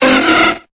Cri de Goélise dans Pokémon Épée et Bouclier.